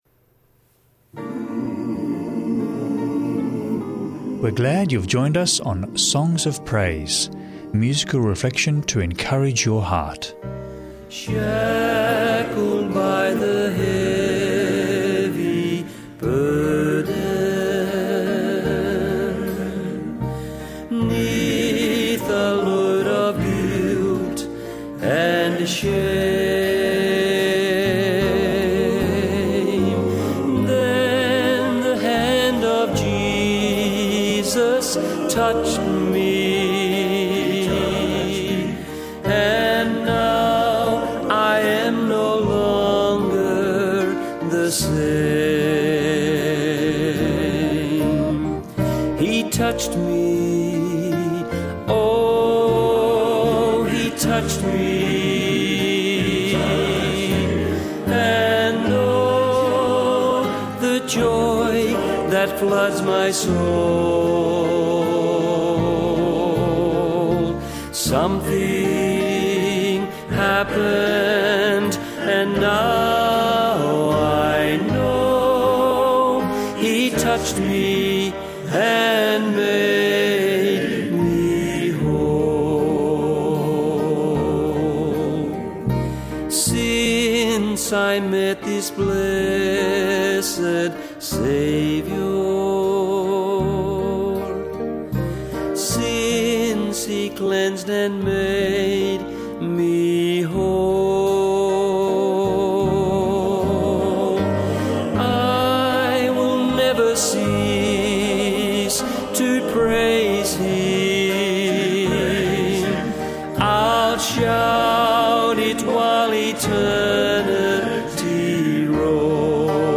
Book Reading